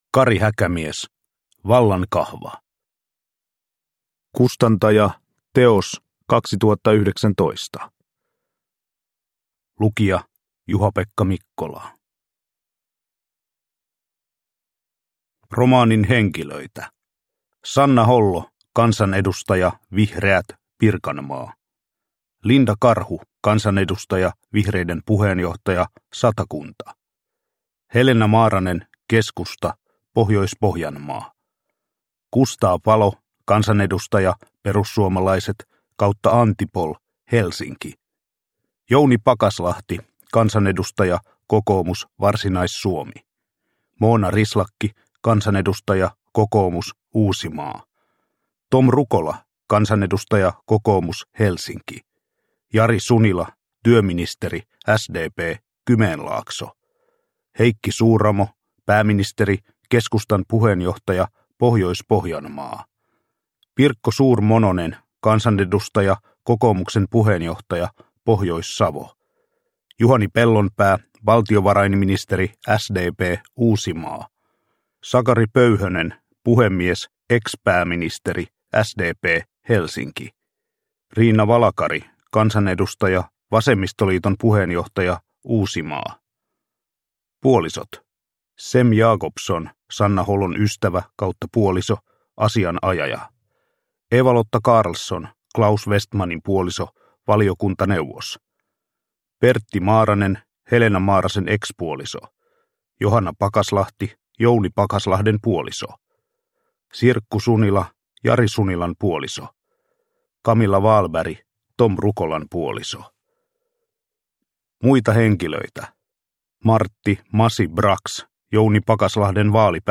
Vallankahva – Ljudbok – Laddas ner